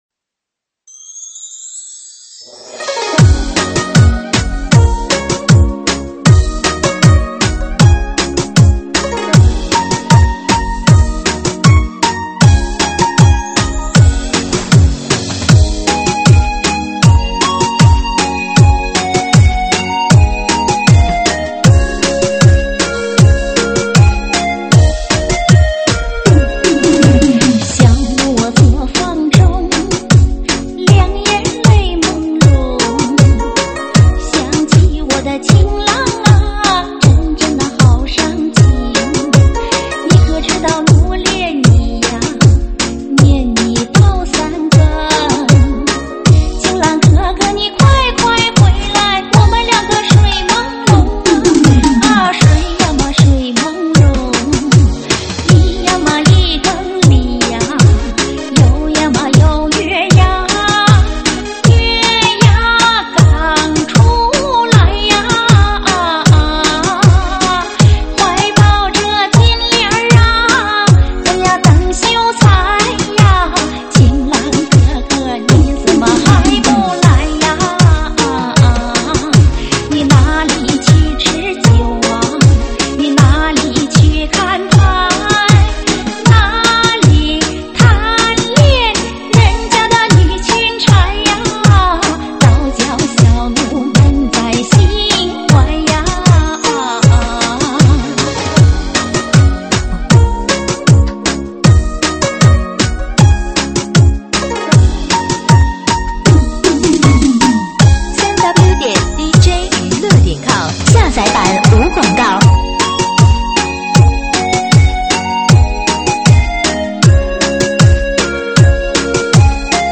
收录于(水兵舞)